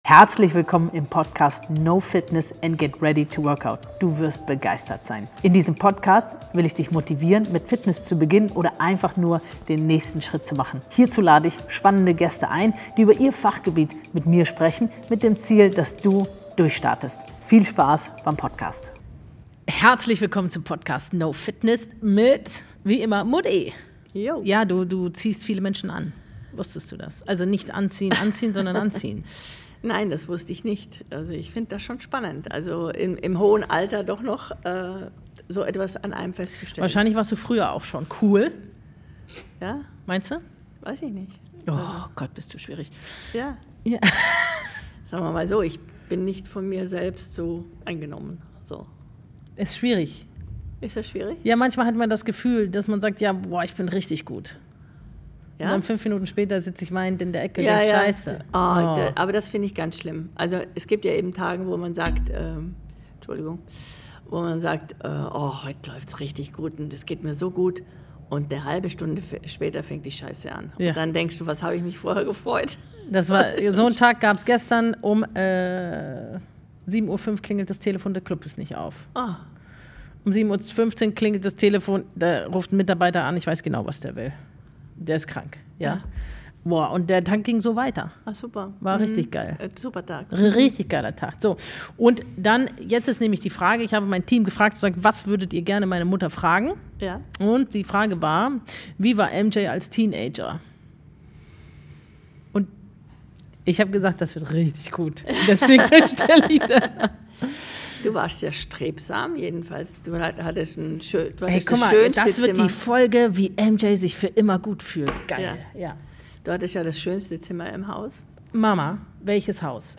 Die beiden sprechen über Verantwortung, Durchhaltevermögen und darüber, wie man in schwierigen Momenten Haltung bewahrt.